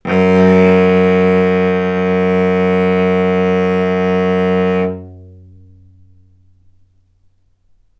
vc-F#2-ff.AIF